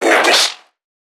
NPC_Creatures_Vocalisations_Infected [66].wav